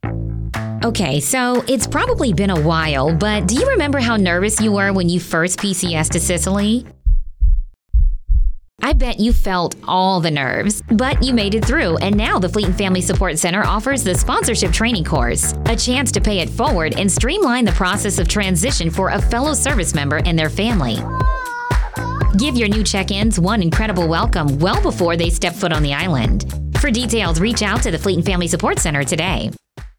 NAVAL AIR STATION SIGONELLA, Italy (March 17, 2026) Radio spot promotes a sponsorship course offered through the Fleet and Family Support Center.